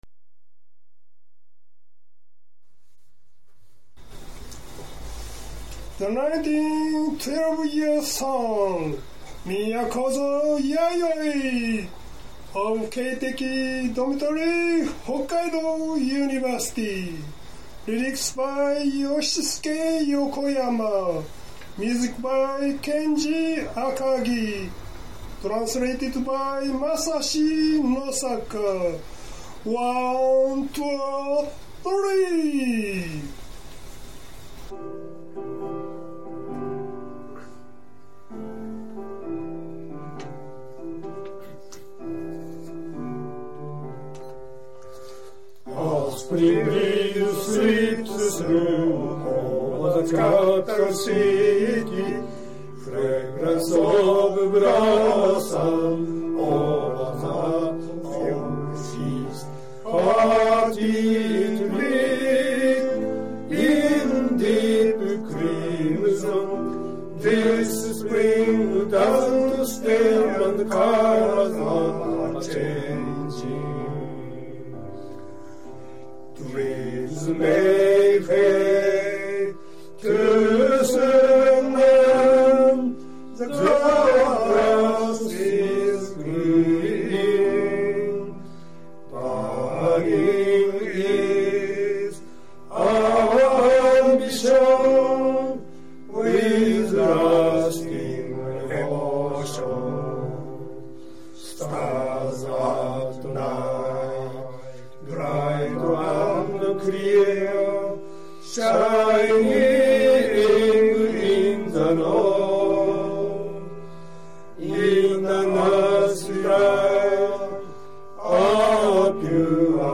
合唱；（1番,　2番,　3番,　4番,　5番）